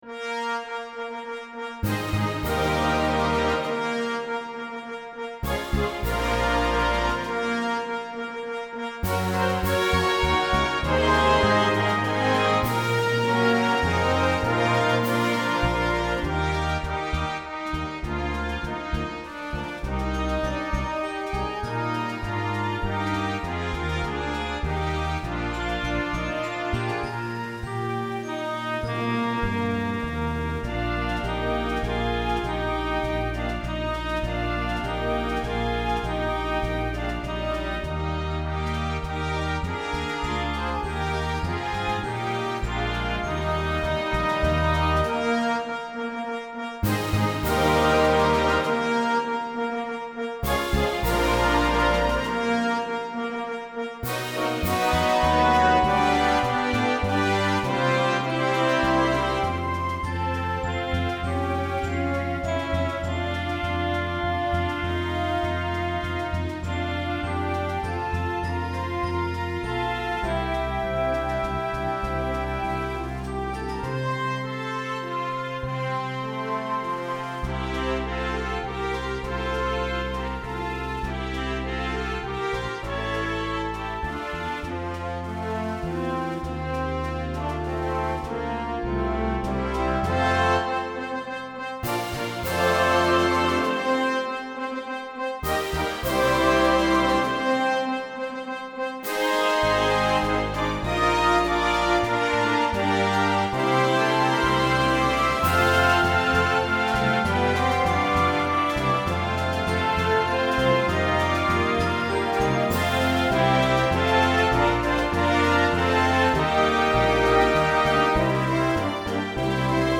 A majestic arrangement